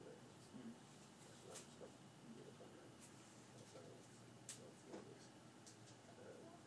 Office